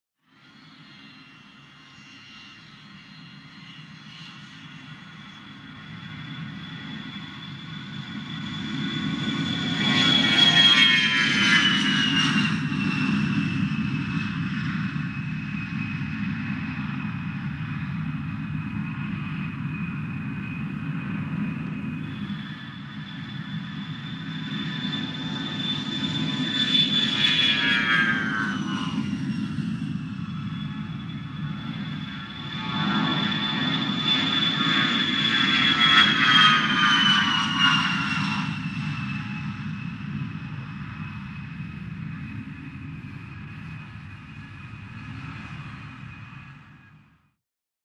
Fly Bys | Sneak On The Lot
AIRCRAFT JETS FLY BYS: EXT: Landings. Multiple aircraft bys.